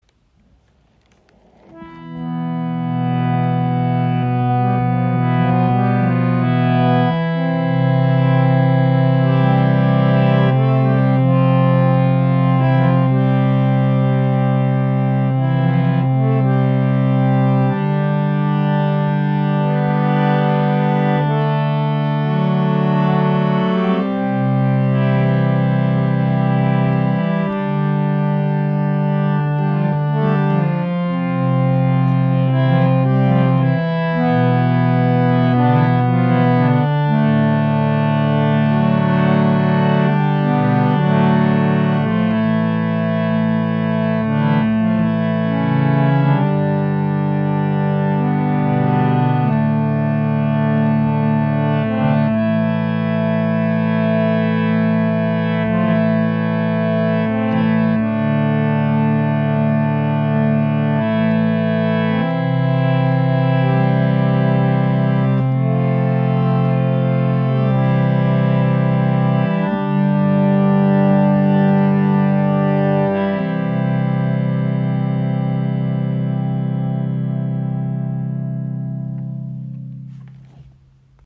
"Radha" Reise Koffer Harmonium mit 3¼ Oktaven | Zedernholz Edition | 440 Hz
• Icon Warmer, tragender Klang mit Seele für Gesang und Klangarbeit
Das Radha Harmonium als leichtere Zedernholz Edition verbindet warme, volle Klangfarben mit einer präzisen Ansprache.
Sein Klang ist warm und sanft, lässt sich sowohl sehr leise als auch kräftig spielen.
Die speziell eingestellten Federn sorgen für einen weichen, lang anhaltenden Klang – ohne störende Pumpgeräusche.